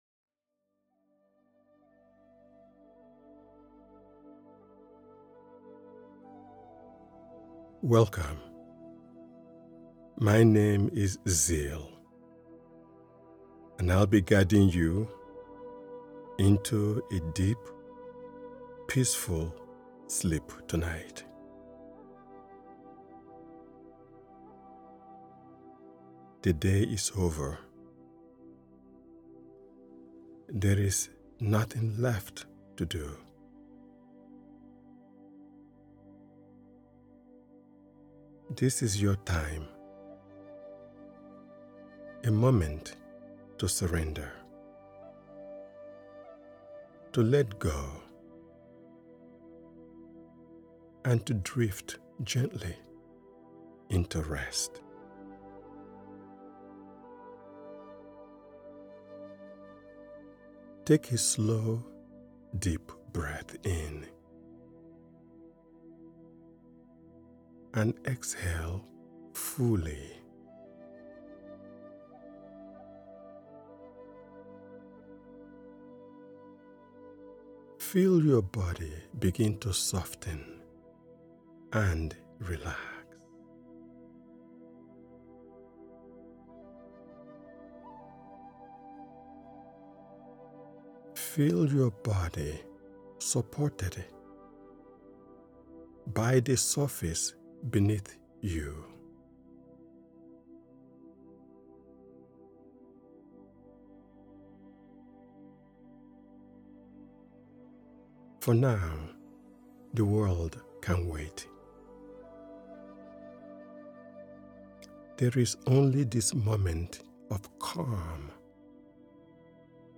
This meditation uses slow breathing cues and calming verbal reassurance to help your body shift into the parasympathetic state—the state where healing, repair, and deep rest naturally occur.
Breathwork for Deep Sleep
Drift-into-deep-sleep.mp3